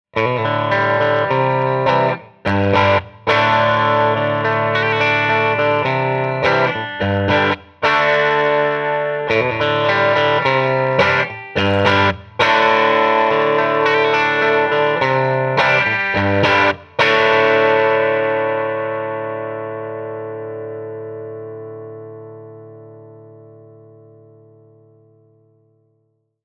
52T (52 to 54 Tele type) alone
50R_52T bridge.mp3